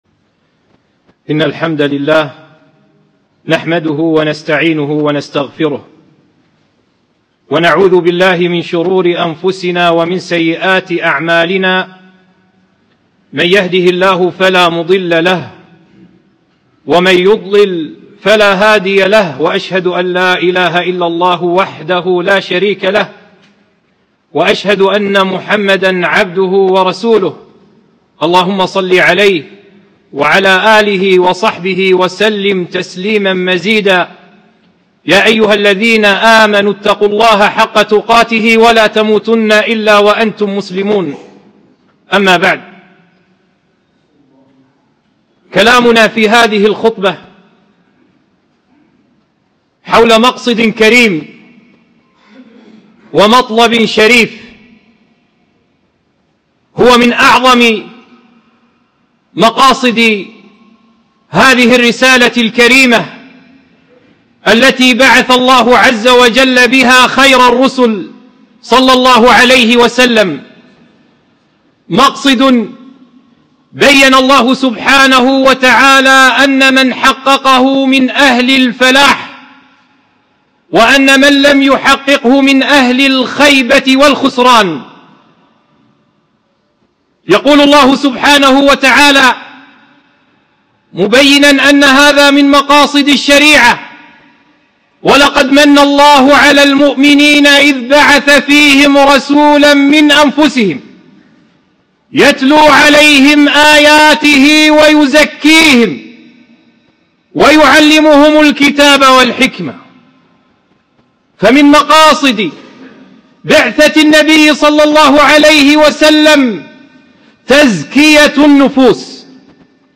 خطبة - فضل تزكية النفس